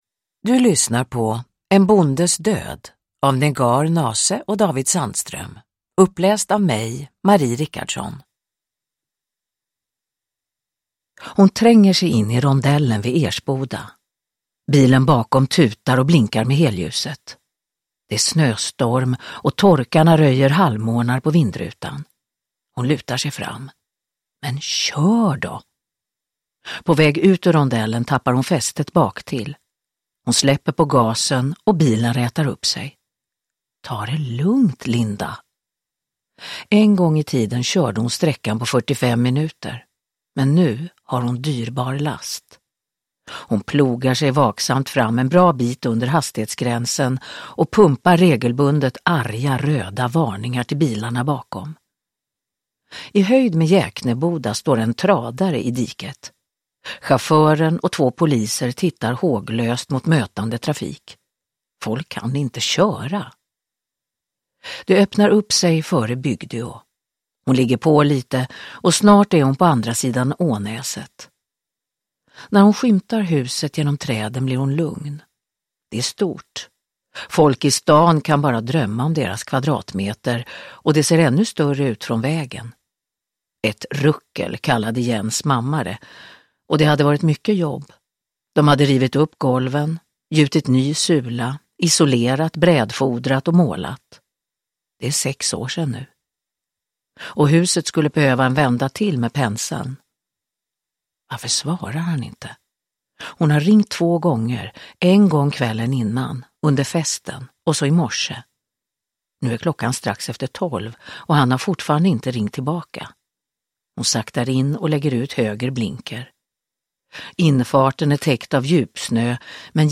En bondes död – Ljudbok – Laddas ner
Uppläsare: Marie Richardson